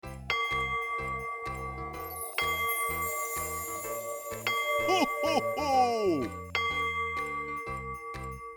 cuckoo-clock-04.wav